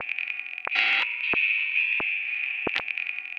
Abstract Rhythm 32.wav